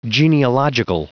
Prononciation du mot genealogical en anglais (fichier audio)
Prononciation du mot : genealogical